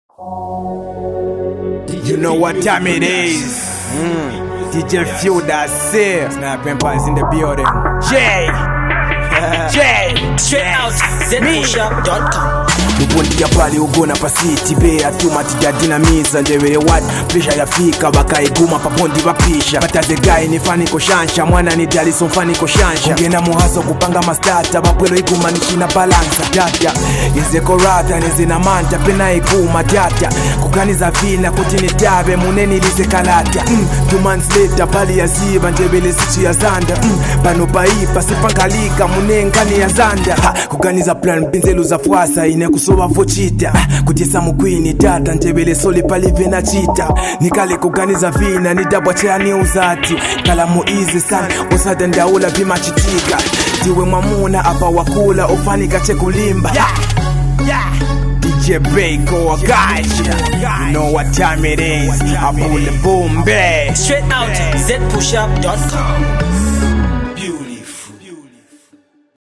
As the Freestyle Rap Sequence continues